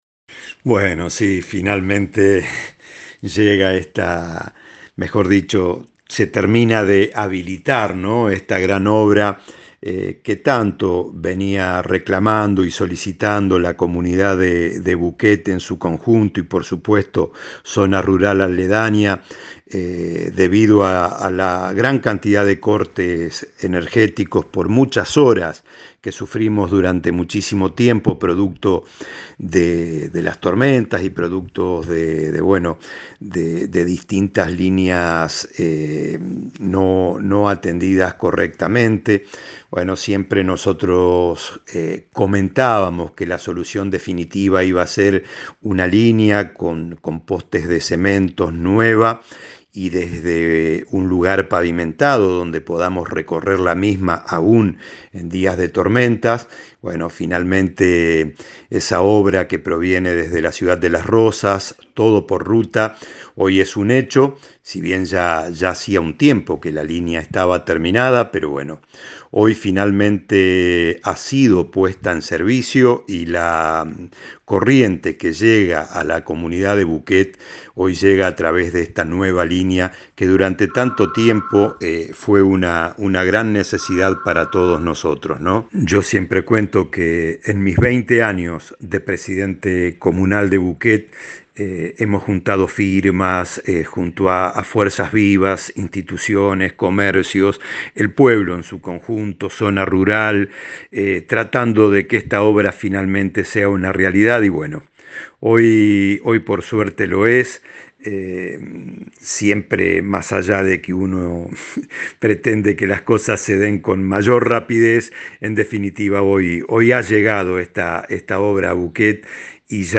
Palabras del Senador Guillermo Cornaglia